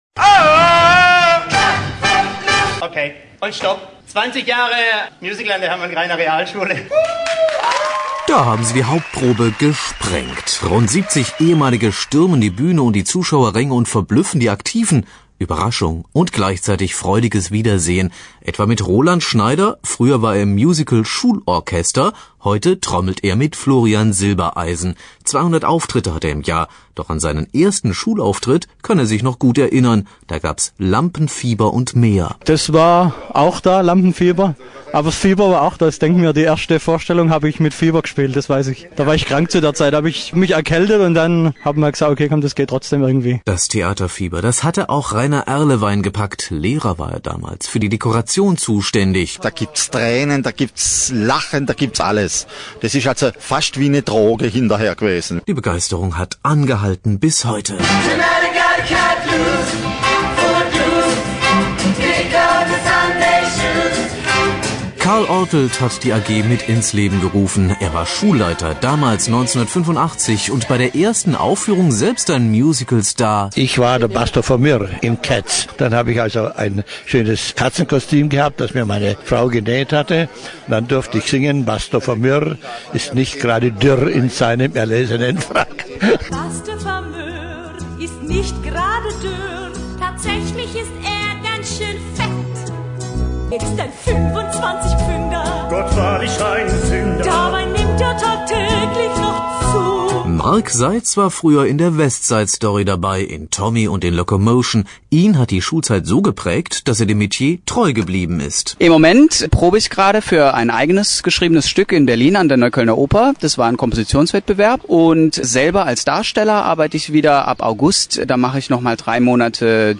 Hörfunkbeitrag des SWR zum 20-jährigen Jubiläum der Musical AG
(SWR, Ausstrahlung vom 14.07.05, Ausschnitt gekürzt auf 2:30 min)